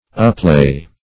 uplay - definition of uplay - synonyms, pronunciation, spelling from Free Dictionary Search Result for " uplay" : The Collaborative International Dictionary of English v.0.48: Uplay \Up*lay"\, v. t. To hoard.